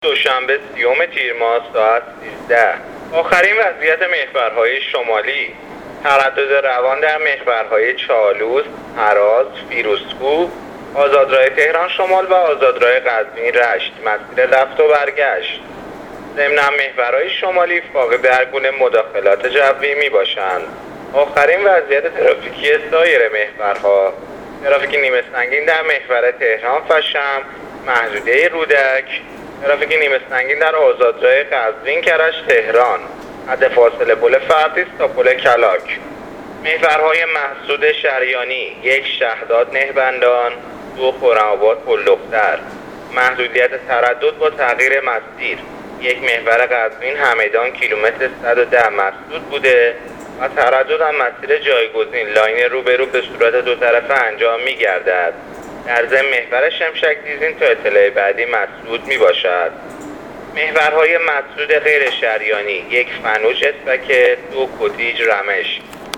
گزارش رادیواینترنتی از وضعیت ترافیکی جاده‌ها تا ساعت ۱۳ دوشنبه ۳۰ تیر